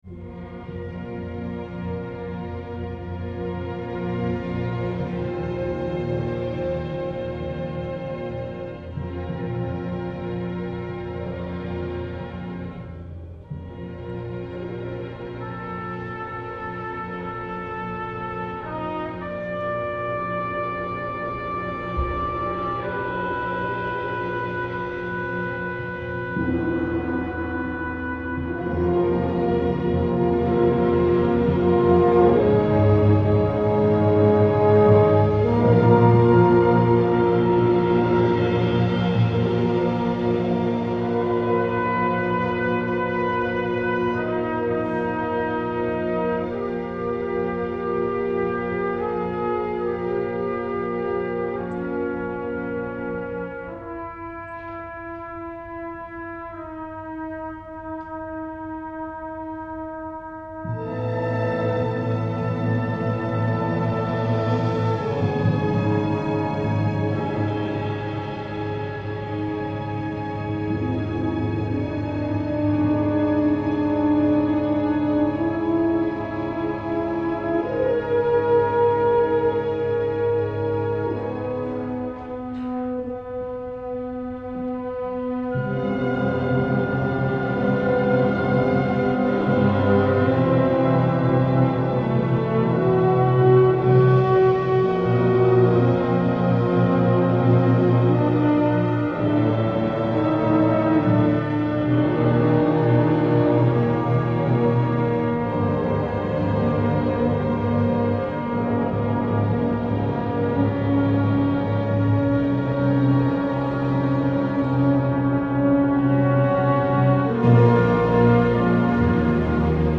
3-3-2-2, 4-2-3-1, Timp., Perc. (3), Str.
orchestra tone poem